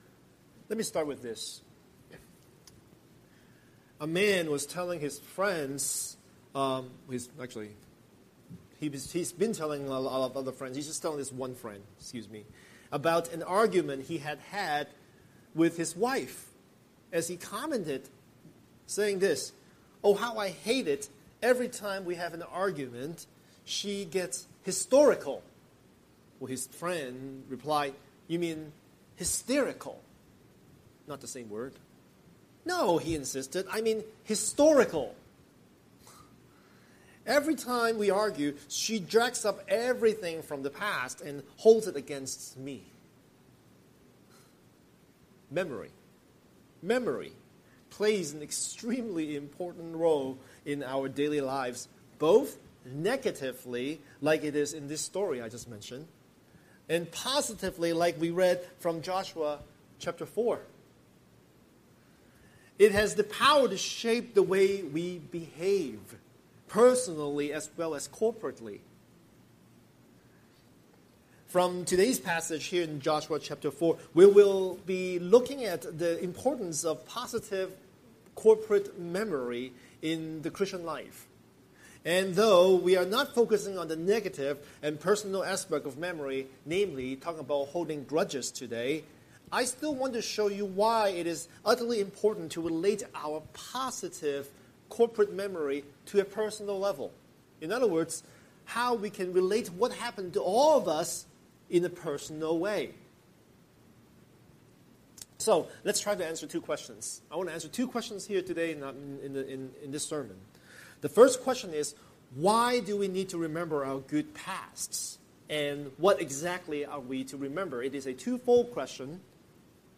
Scripture: Joshua 4:1–24 Series: Sunday Sermon